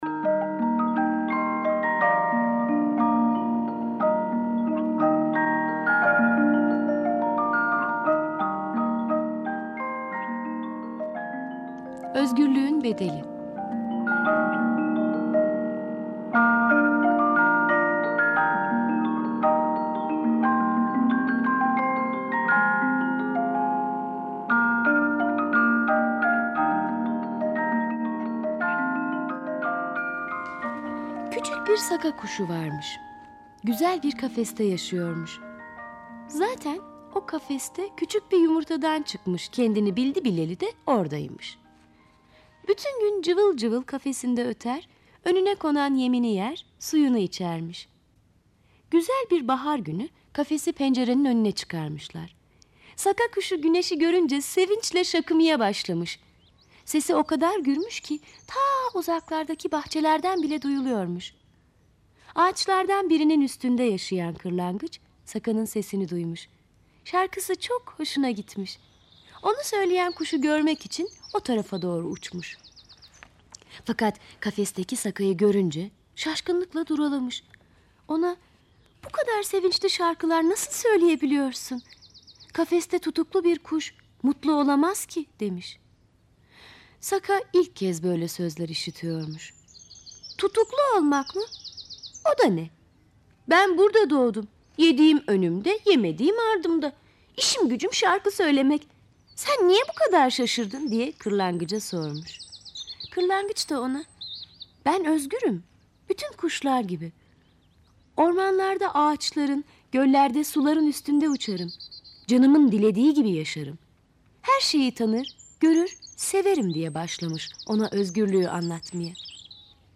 Kategori Sesli Çocuk Masalları